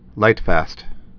(lītfăst)